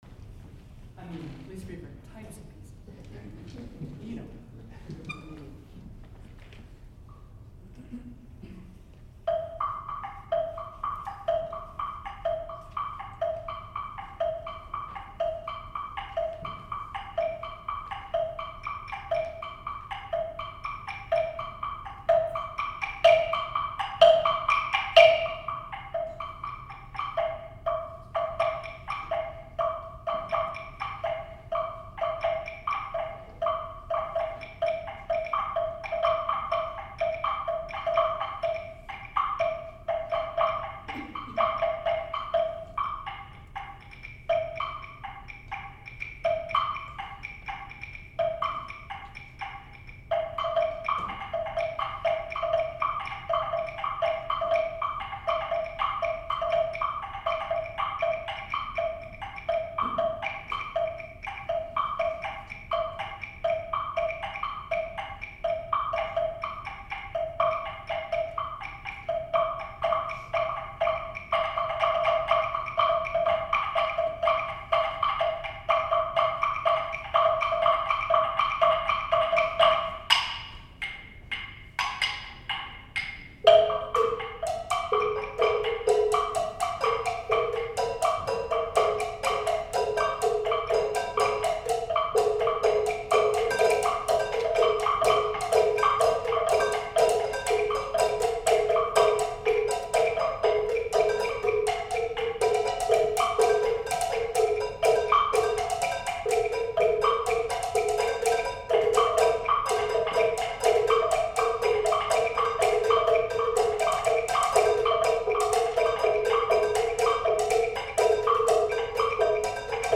The wood group consists of claves, woodblock, and temple block; the metal group of cowbells and a frying pan; the shakers, of  maracas and a shekere; and the drums of bongos, congas, and tom-toms.
A drum duo leads to the second large section, in which all four groups are heard in densely layered rhythms, again with short breaks featuring each group.  The piece concludes with an extended passage of repeated rhythms, before a brief unison coda.